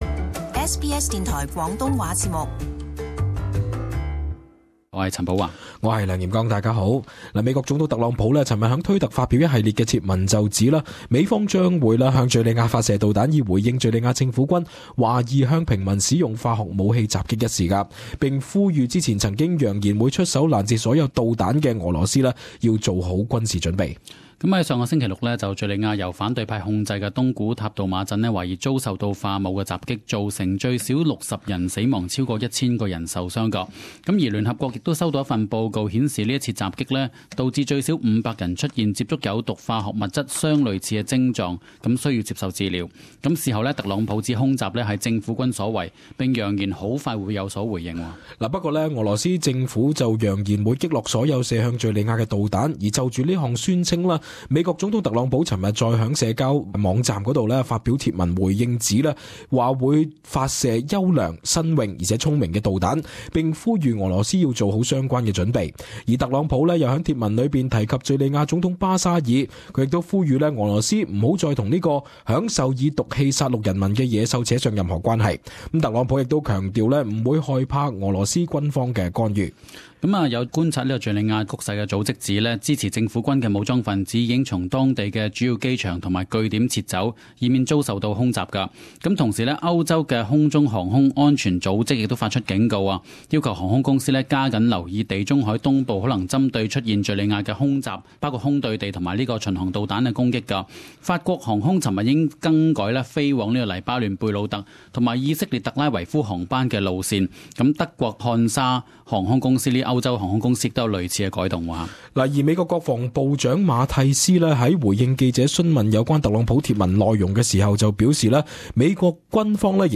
【时事报导】特朗普向俄国扬言将向叙利亚射导弹